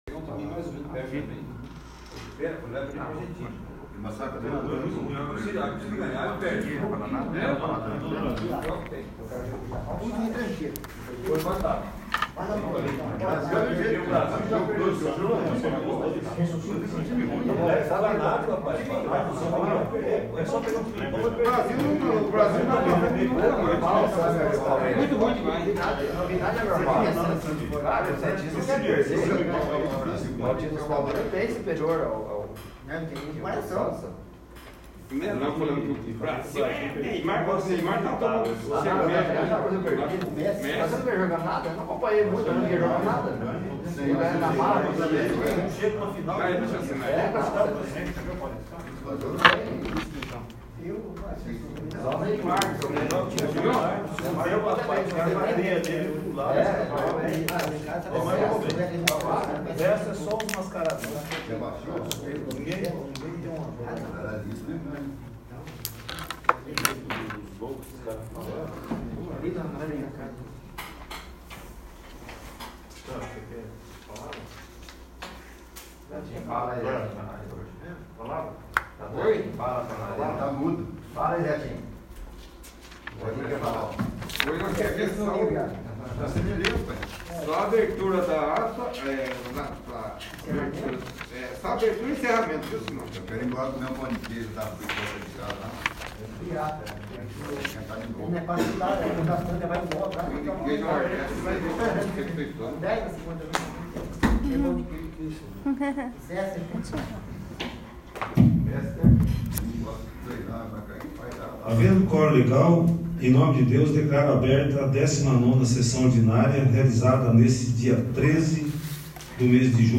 19º. Sessão Ordinária